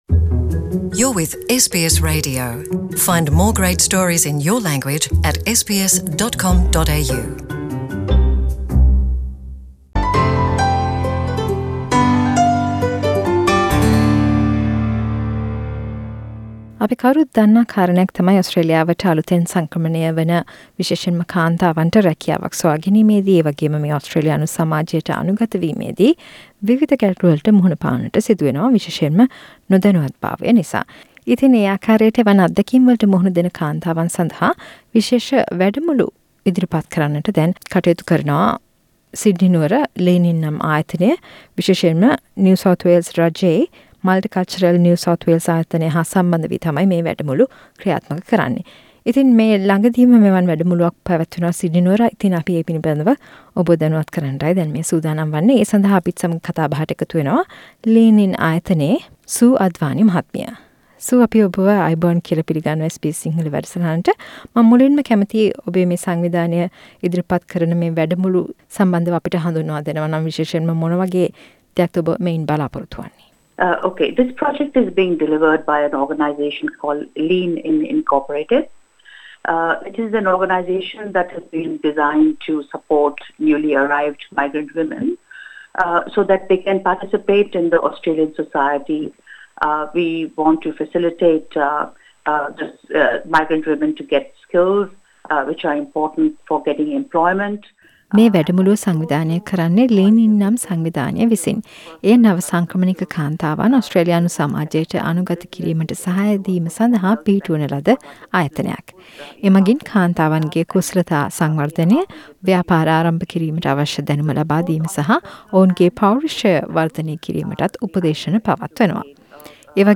සාකච්ඡාවක්